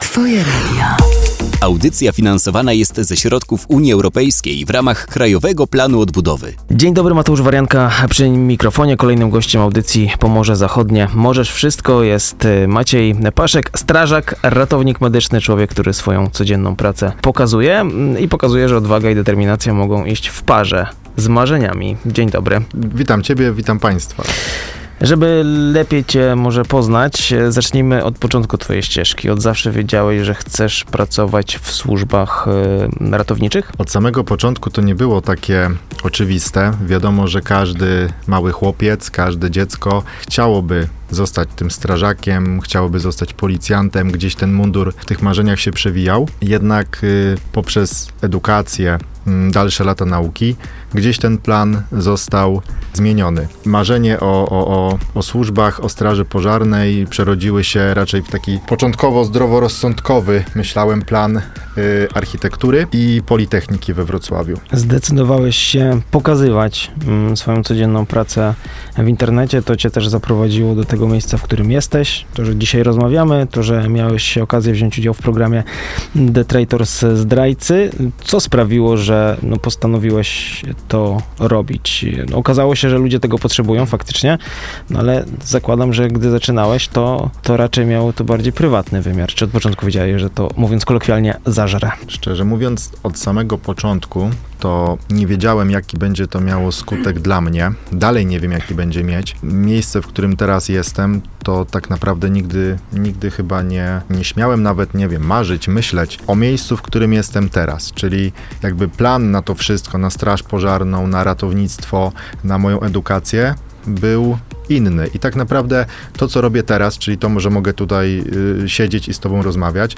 Wywiad, który mogliście usłyszeć na antenie Twojego Radia, jest już dostępny w formie podcastu!